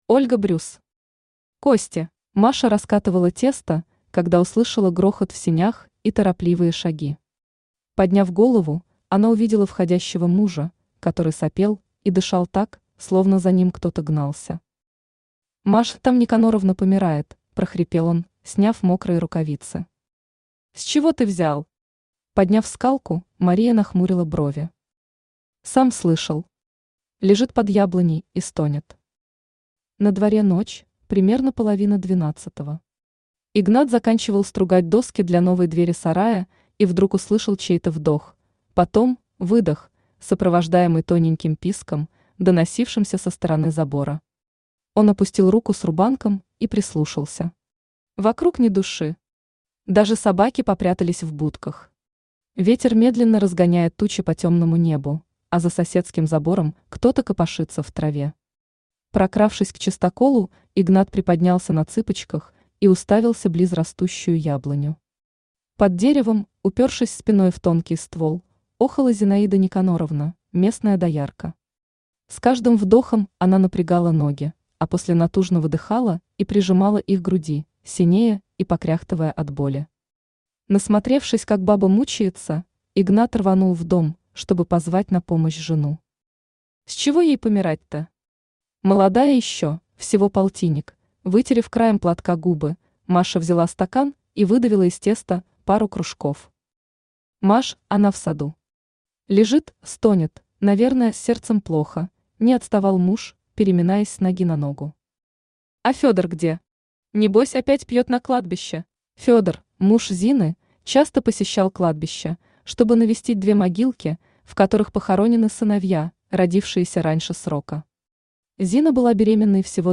Аудиокнига Кости | Библиотека аудиокниг
Aудиокнига Кости Автор Ольга Брюс Читает аудиокнигу Авточтец ЛитРес.